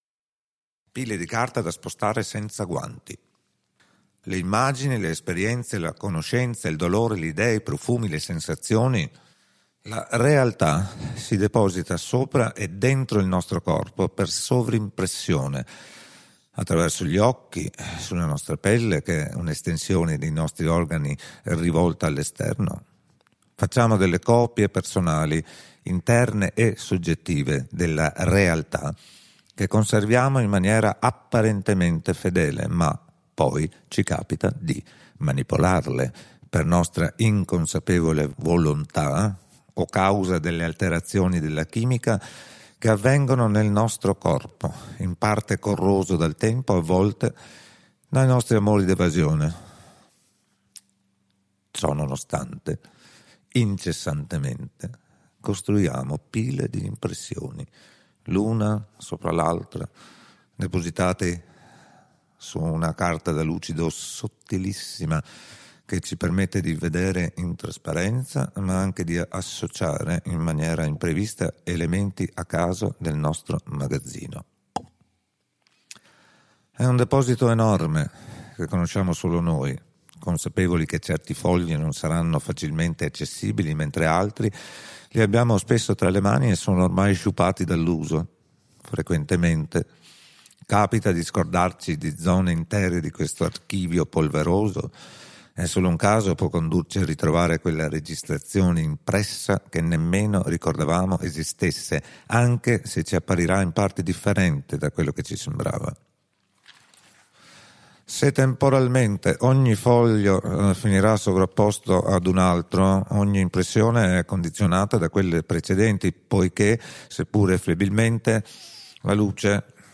Pile di carta da lucido da spostare senza guanti, voce Vitaliano Trevisan